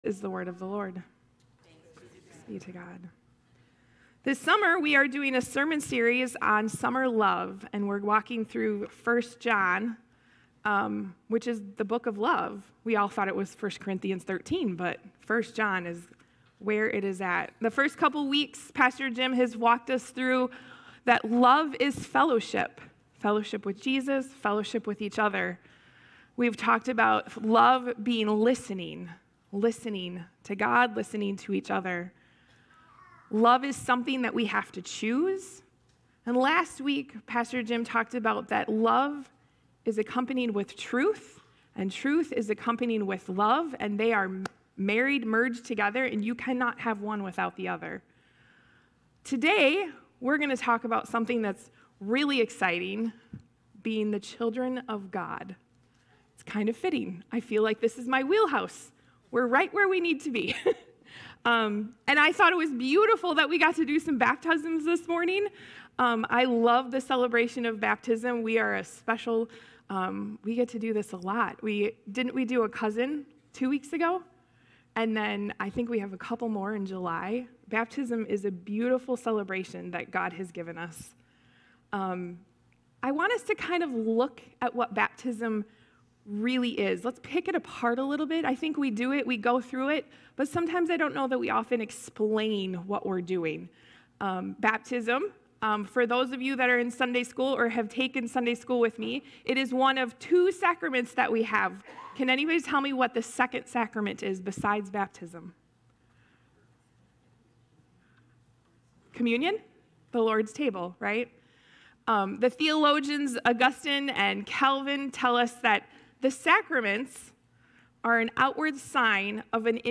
6-29-25+Sermon.mp3